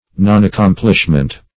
Meaning of nonaccomplishment. nonaccomplishment synonyms, pronunciation, spelling and more from Free Dictionary.
nonaccomplishment.mp3